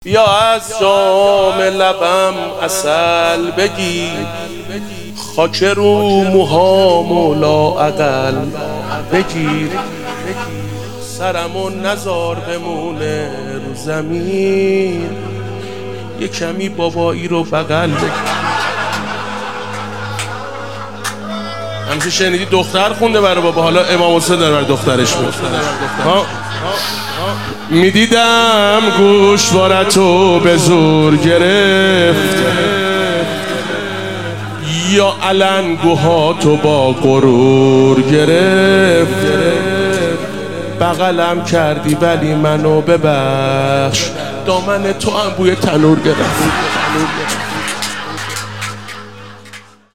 روضه خوانی
ولادت حضرت رقیه سلام الله علیها 1401